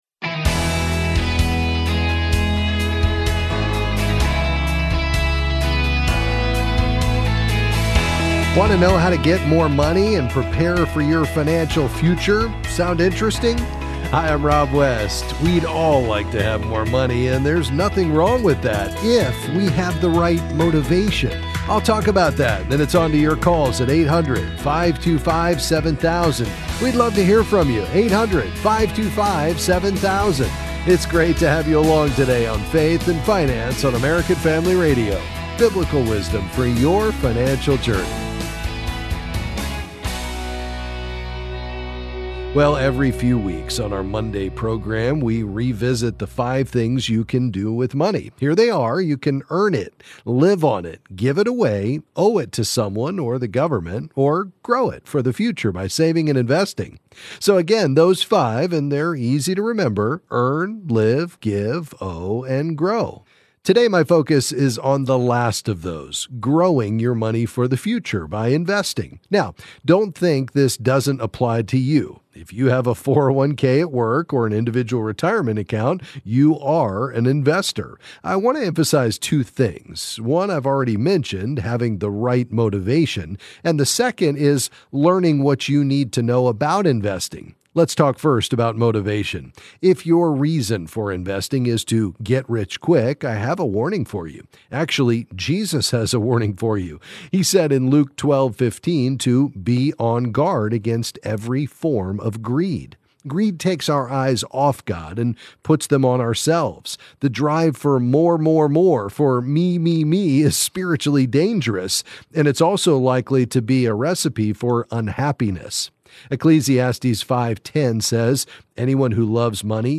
Then he'll answer your calls on various financial topics.